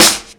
Tuned snare samples Free sound effects and audio clips
• Dirty Snare E Key 03.wav
Royality free steel snare drum sample tuned to the E note. Loudest frequency: 5116Hz
dirty-snare-e-key-03-4Hx.wav